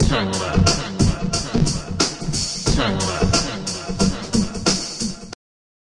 嘻哈之声1 " 转过身来，嗨，嘻哈之声的声音效果
描述：嘻哈放克人声样本
标签： 混响 回声 环境 机器人 样品 抽象的 人声 节拍 髋关节
声道立体声